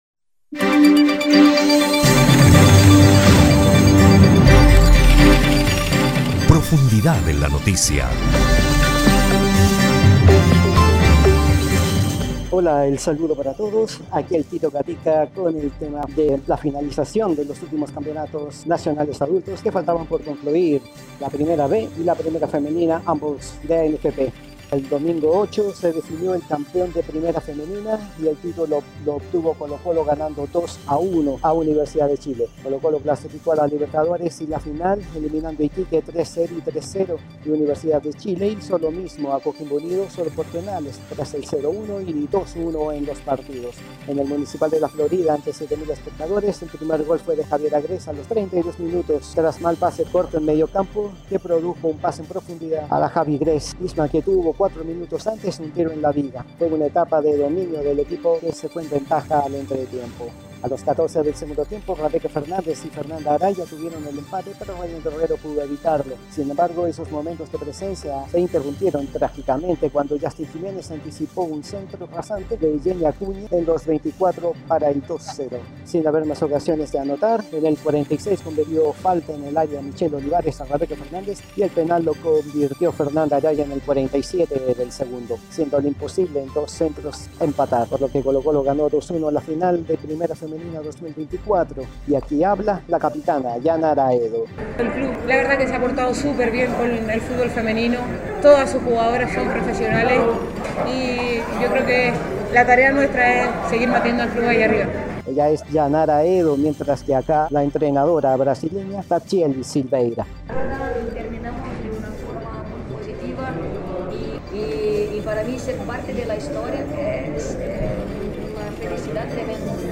[Podcast] Informe de la final primera femenina en que Colo Colo venció a la U y obtuvo el tricampeonato